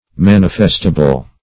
Manifestable \Man"i*fest`a*ble\, a. Such as can be manifested.